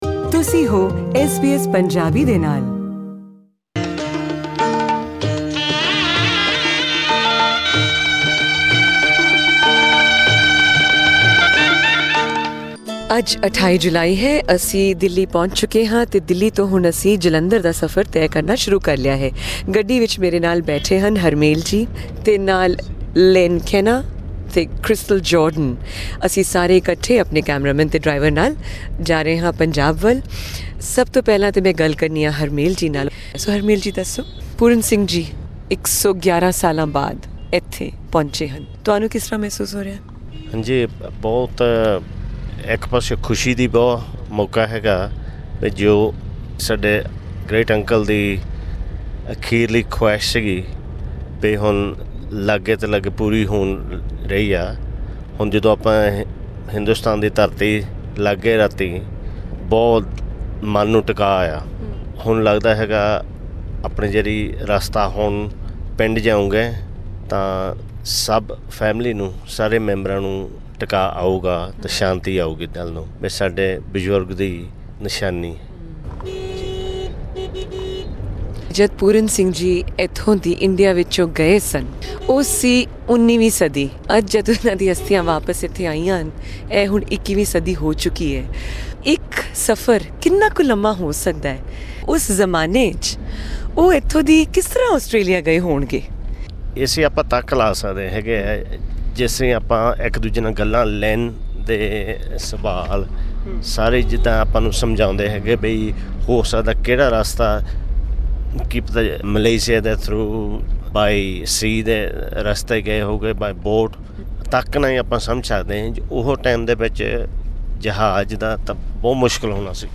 Here is the fourth episode of the audio documentary Pooran Singh's final journey, first broadcast on SBS Punjabi in August 2010.